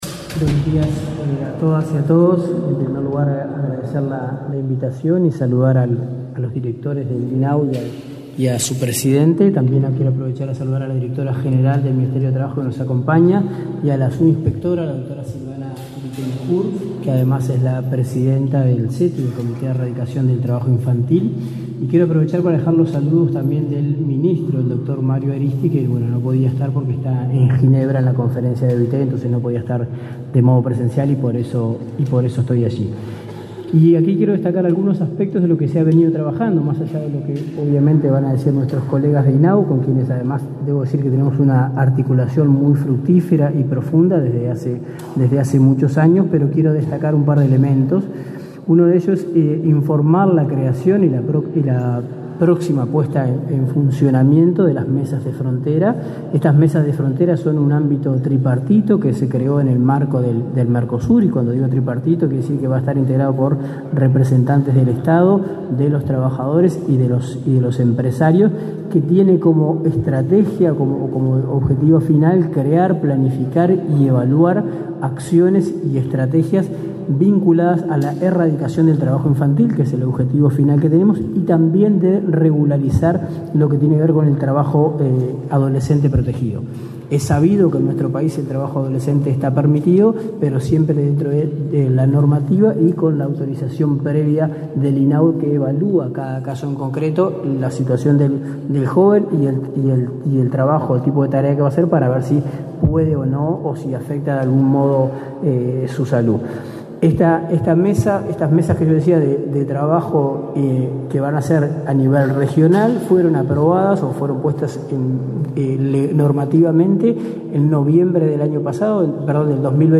El Instituto del Niño y el Adolescente del Uruguay (INAU) realizó, este 12 de junio, un acto con motivo del Día Mundial contra el Trabajo Infantil.
Participaron el ministro interino de Trabajo, Daniel Pérez, y la directora, la directora general y el presidente del INAU, Natalia Argenzio, Dinorah Gallo y Guillermo Fossatti, respectivamente.